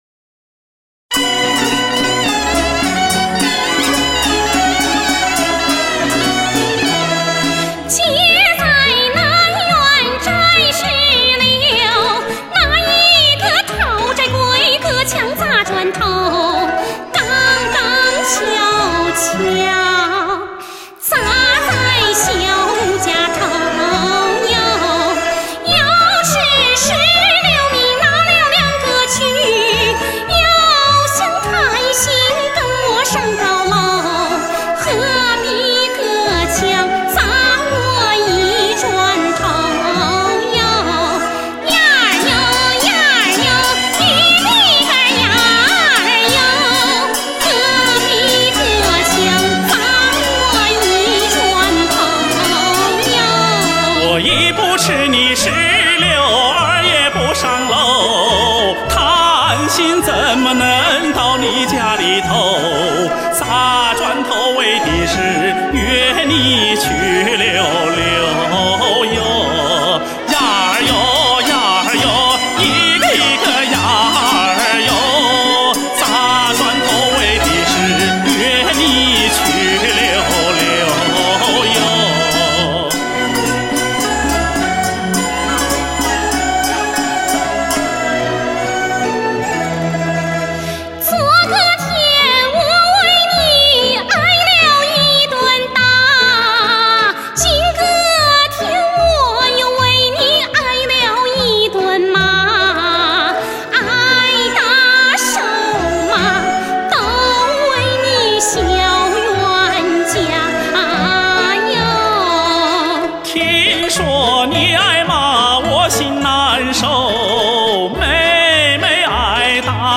演唱的歌手虽然采取不同的演唱方式，但都保持了民歌特有的风格，他们是在用情演唱、用心歌唱、用爱咏唱。